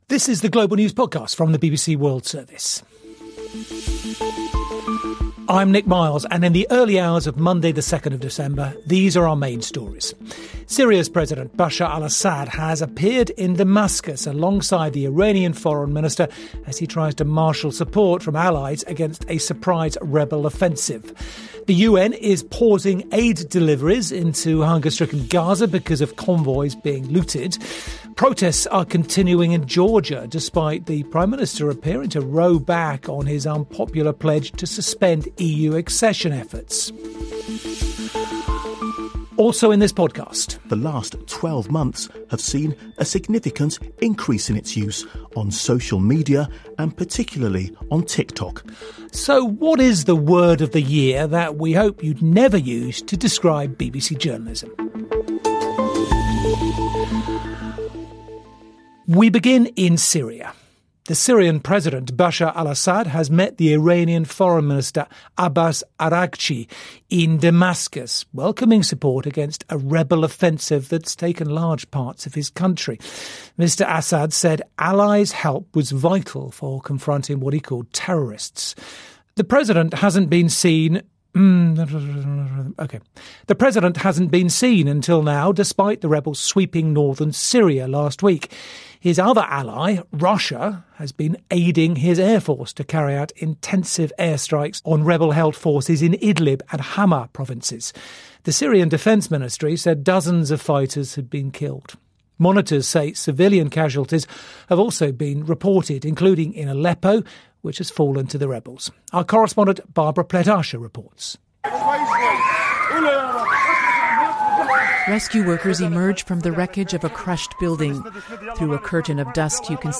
The best stories, interviews and on the spot reporting from around the world including highlights from News hour, The World Today and World Briefing. Up to 30 minutes compiled twice a day from the 24 hour News coverage from the BBC World Service.